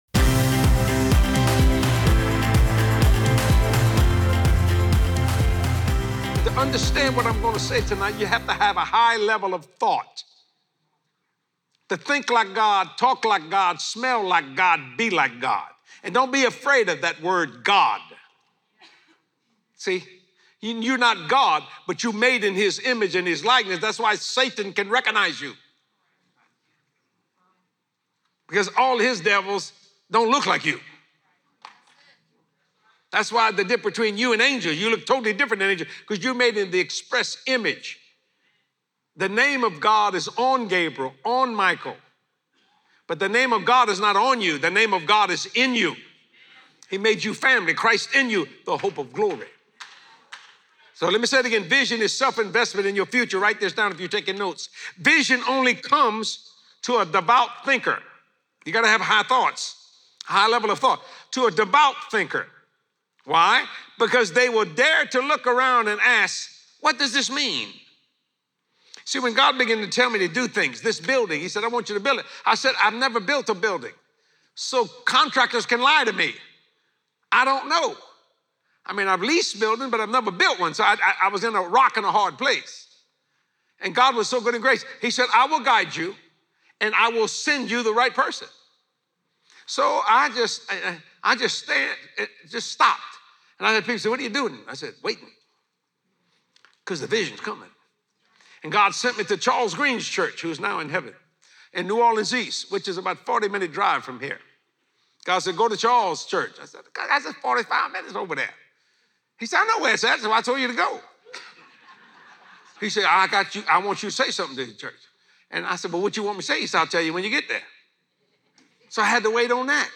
Expand your VISION more than ever before as you watch this special Faith the Facts with Jesse from a past Visionary Conference.